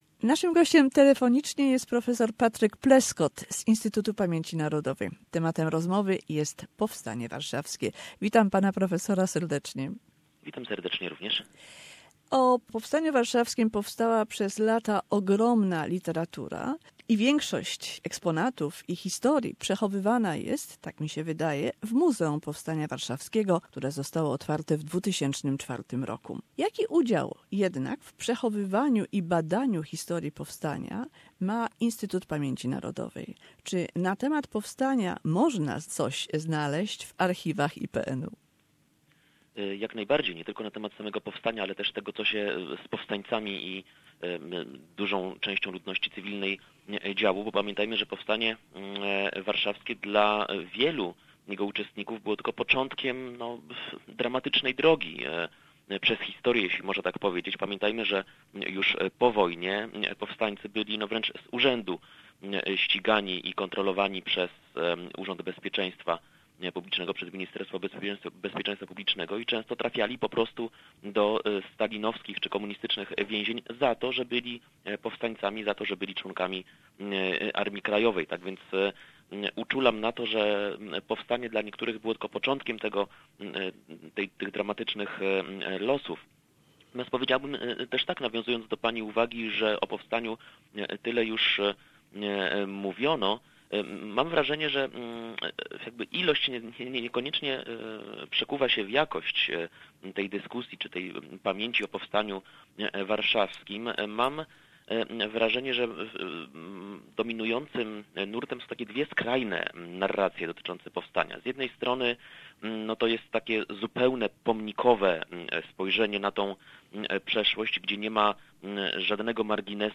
Historian on the Warsaw Uprising